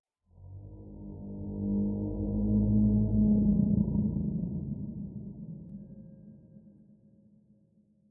Minecraft Version Minecraft Version latest Latest Release | Latest Snapshot latest / assets / minecraft / sounds / ambient / underwater / additions / dark4.ogg Compare With Compare With Latest Release | Latest Snapshot